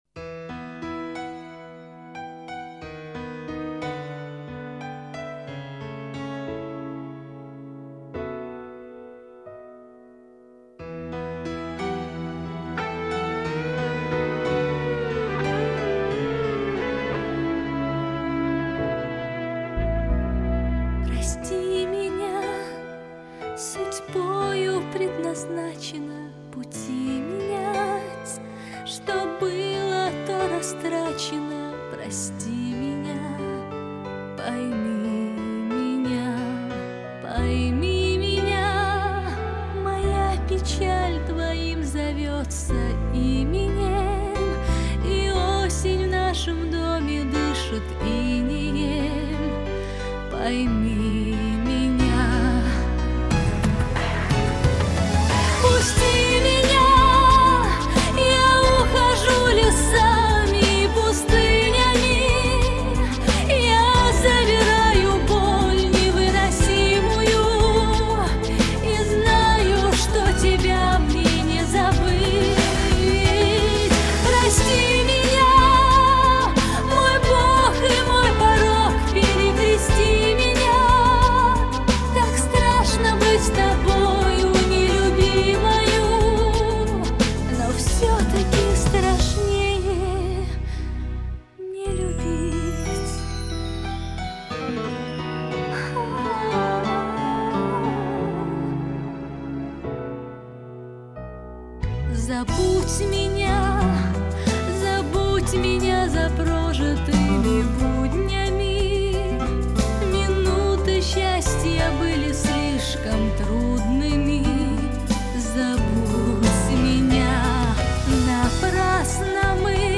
русская песня